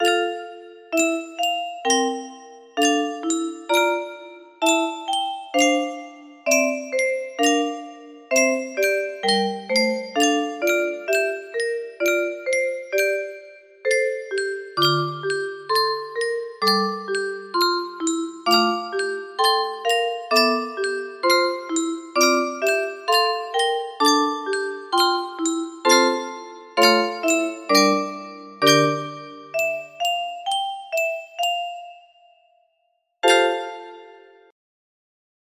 Niebiosa rosę spuście nam z góry music box melody
Arrangement of popular Polish Advent hymn